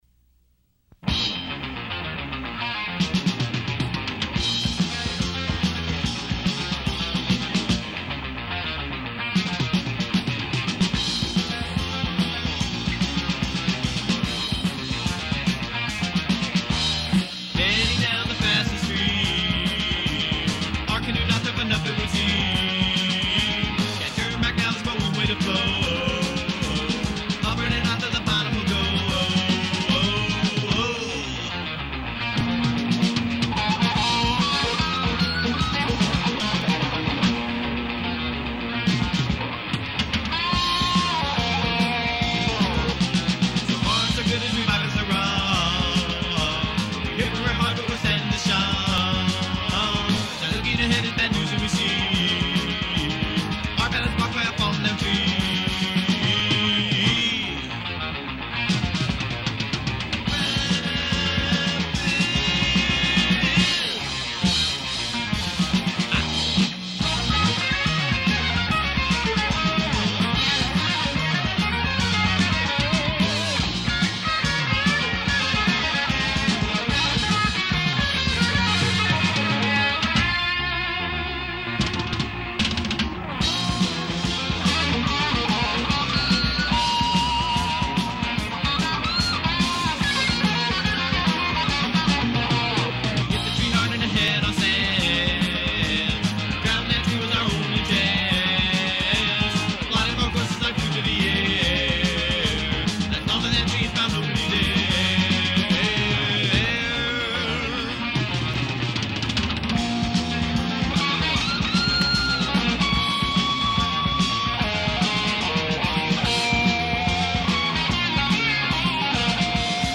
Most of those recordings were made in the basement on a 4-track reel-to-reel. This was one of my first forays into the heavier side of rock.